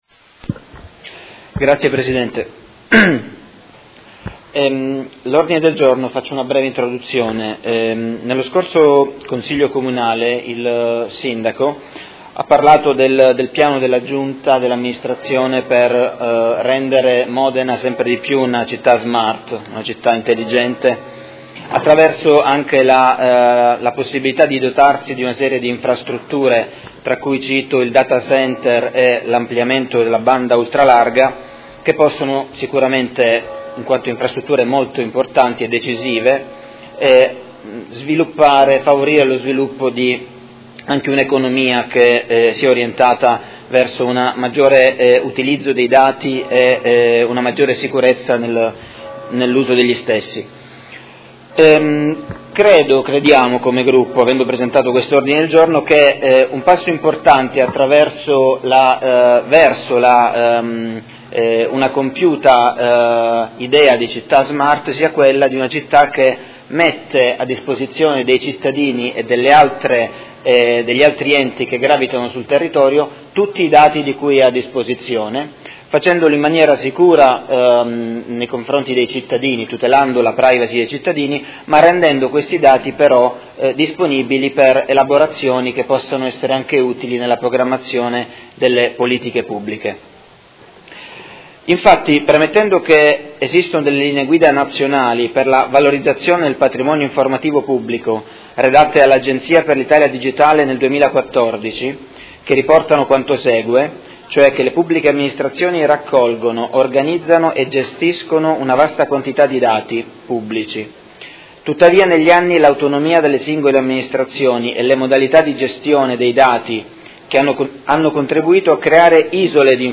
Seduta del 29/09/2016 Ordine del Giorno presentato dai Consiglieri Fasano, Pacchioni, Arletti, Stella, Malferrari, Poggi, Liotti, Baracchi, Bortolamasi e Trande (P.D.) avente per oggetto: Supporto alle politiche pubbliche attraverso l’utilizzo e la condivisione di banche dati
Audio Consiglio Comunale